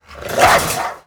VEC3 FX Reverse 41.wav